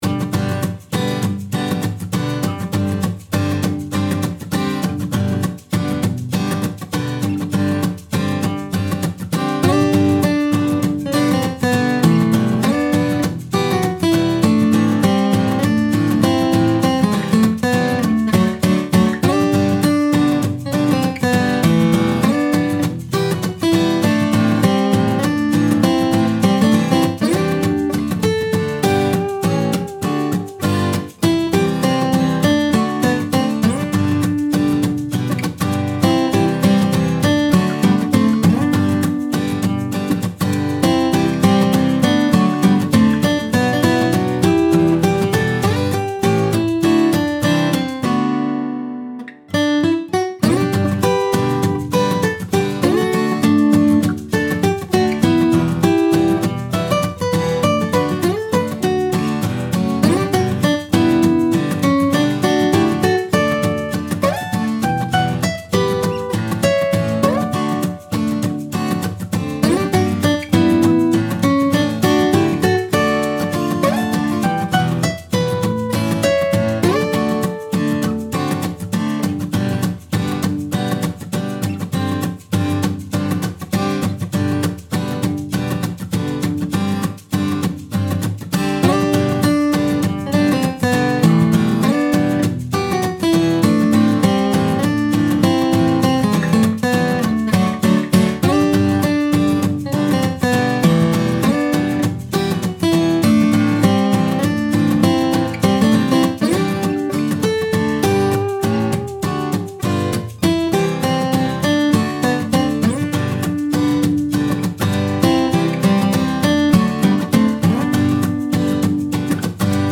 アコースティックギターだけで演奏される軽快なフォーク系BGMです。
爽やかかつ愉快な雰囲気が漂っています。
BPM 100
4. アコースティックギター
11. 穏やか
12. 軽快
22. 明るい